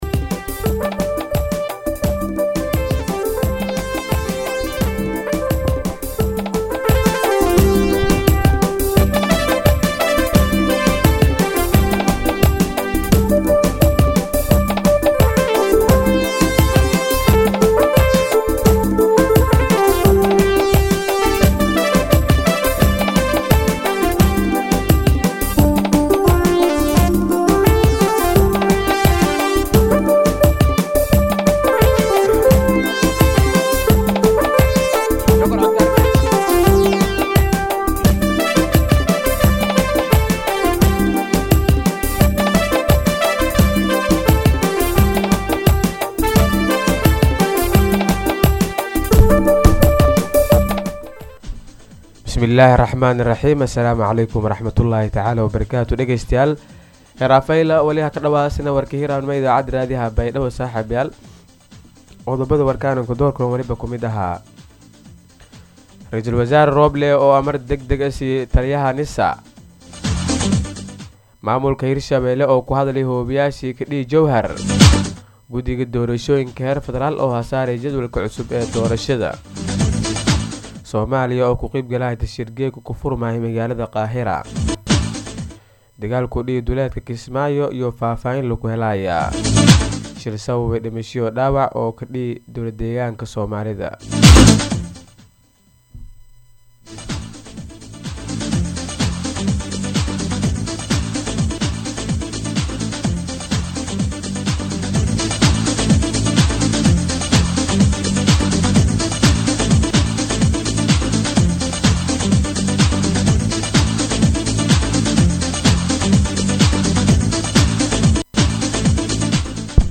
DHAGEYSO:-Warka Subaxnimo Radio Baidoa 5-9-2021